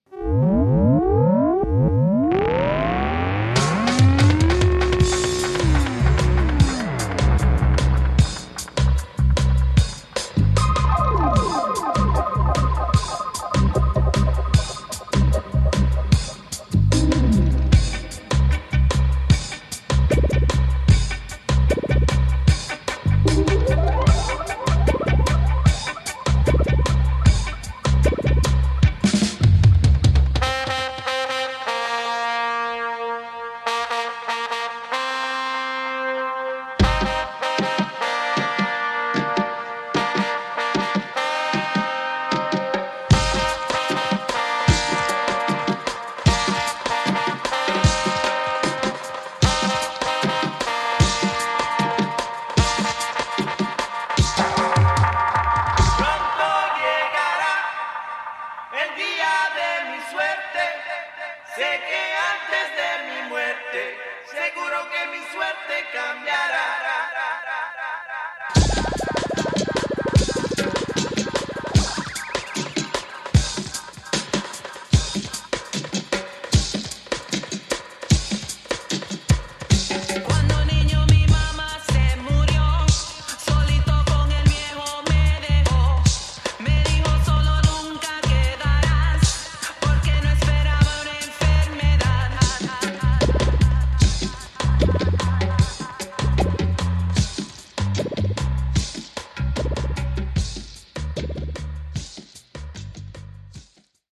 Tags: Reggae , Salsa